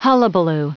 Prononciation du mot hullabaloo en anglais (fichier audio)
Prononciation du mot : hullabaloo